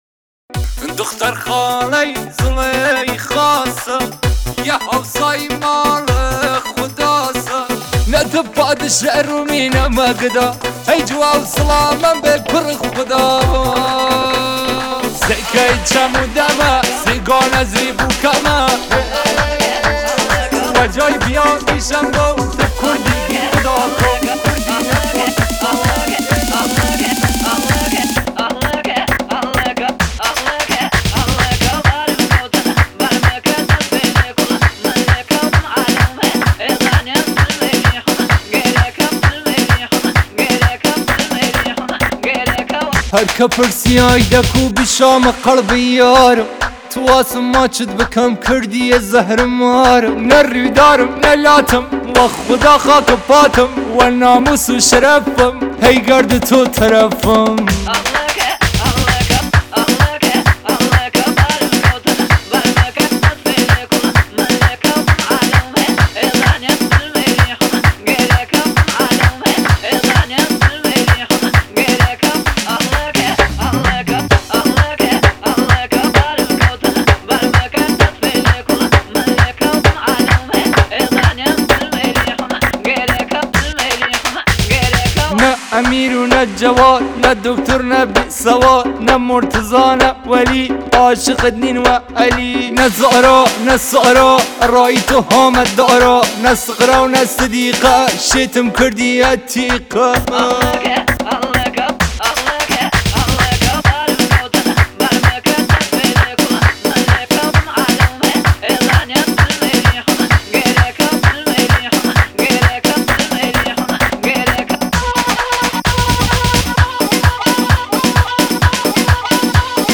( ریمیکس ترانه اضافه شد )
آهنگ های دیگر از کردی